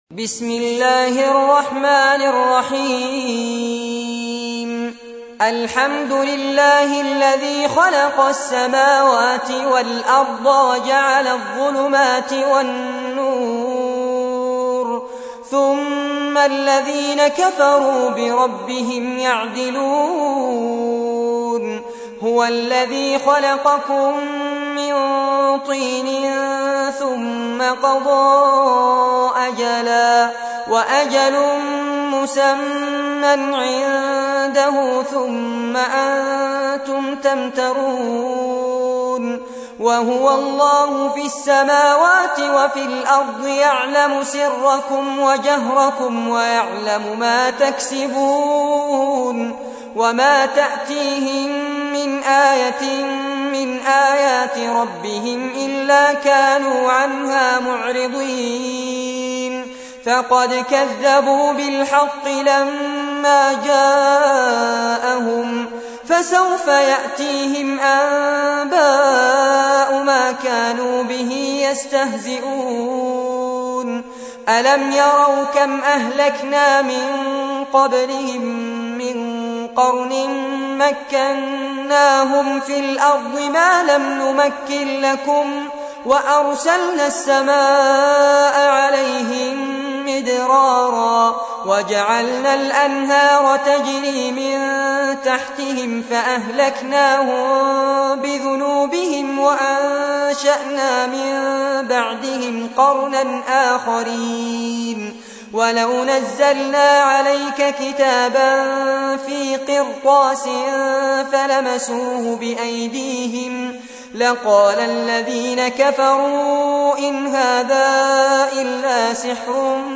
تحميل : 6. سورة الأنعام / القارئ فارس عباد / القرآن الكريم / موقع يا حسين